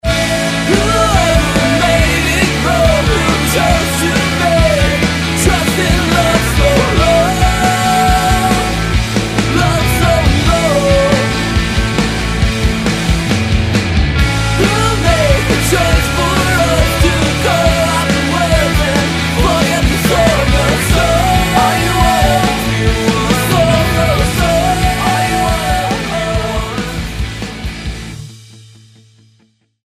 STYLE: Rock
dense, guitar-layered rock
Falling somewhere between power pop and metal